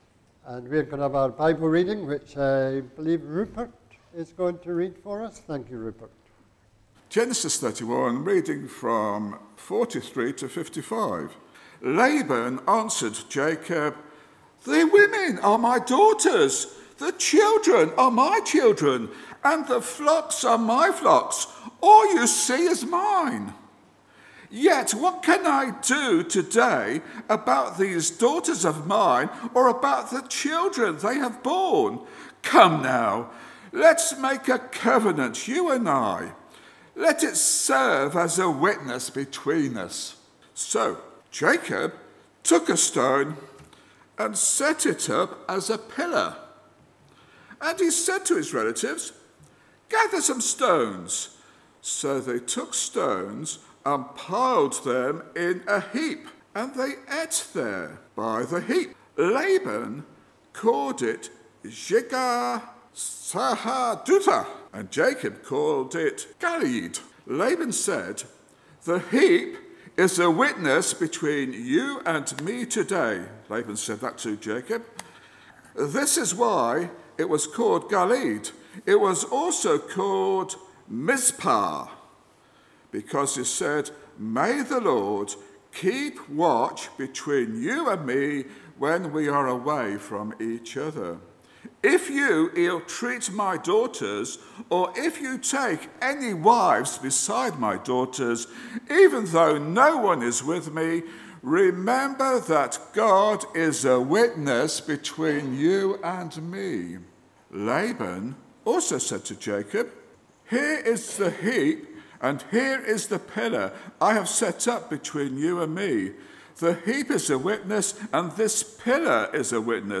Sunday sermons - Park Road Baptist Church